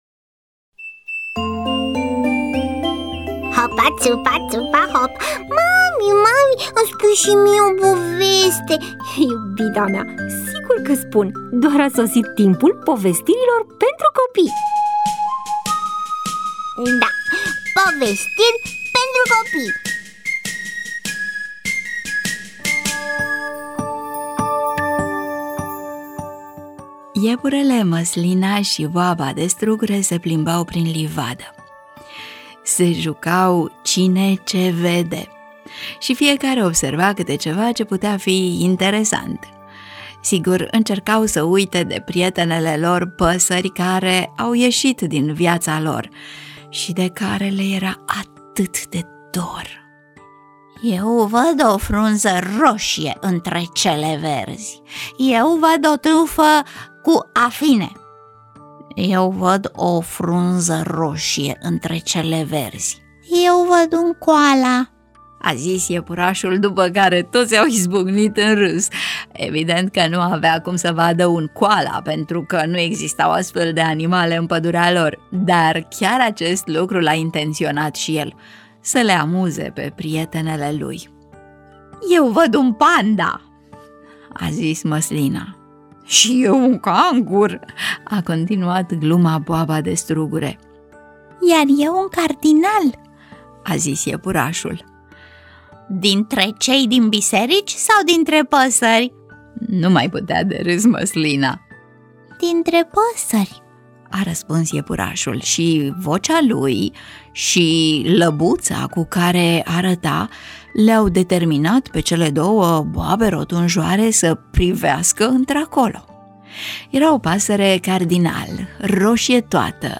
EMISIUNEA: Povestiri pentru copii